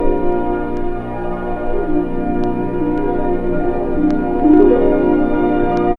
4307L ORCH.wav